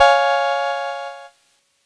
Cheese Chord 07-C#3.wav